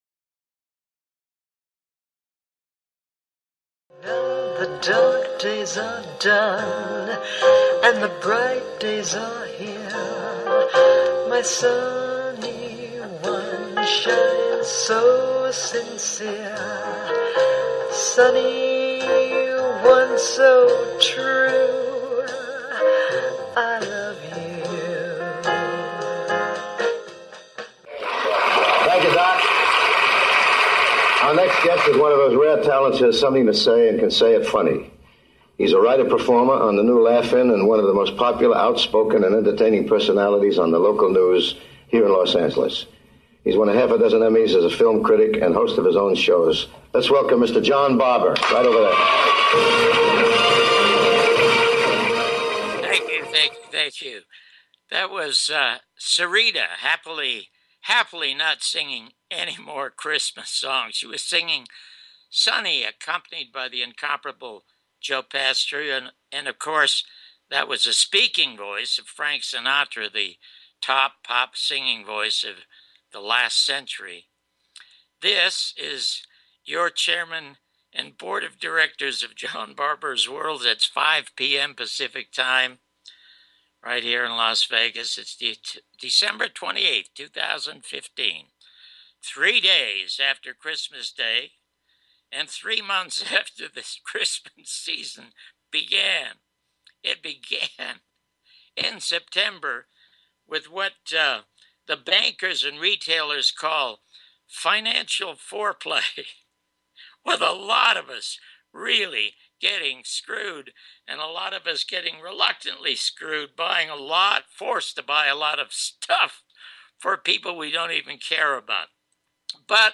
1st Part interview
2nd Part interview